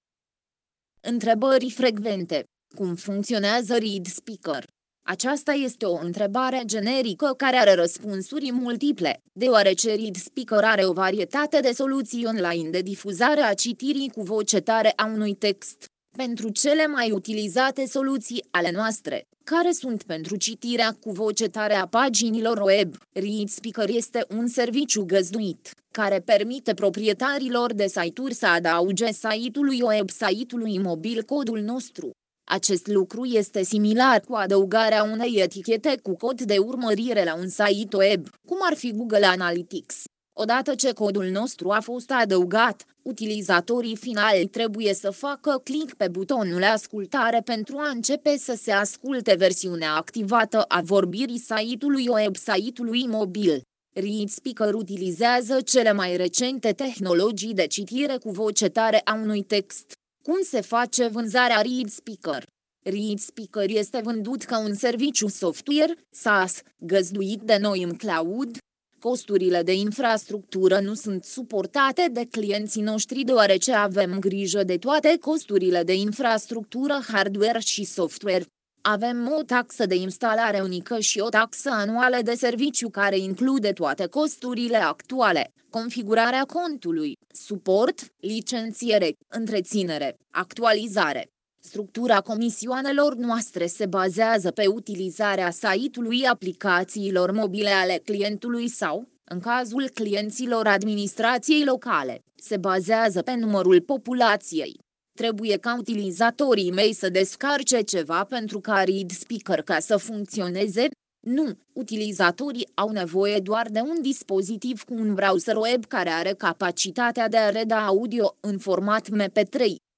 FAQ_RO - TTS.mp3